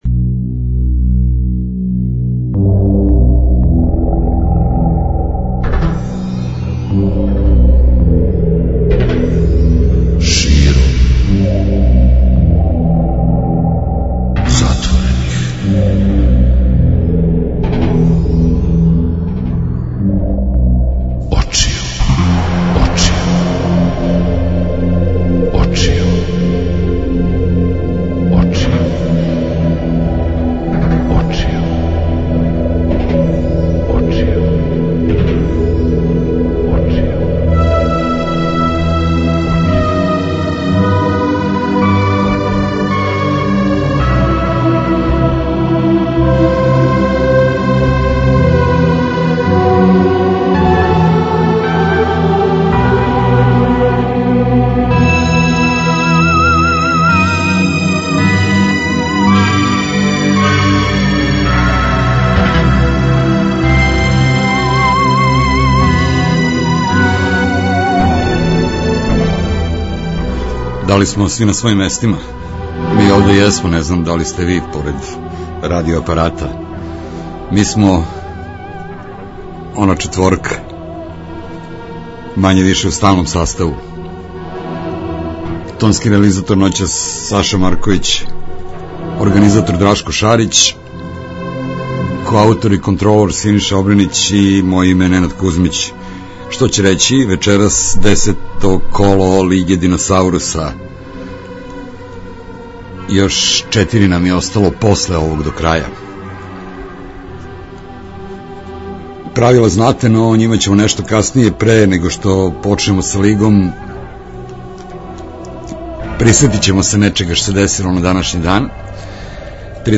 Средом од поноћи - спој добре рок музике, спортског узбуђења и навијачких страсти.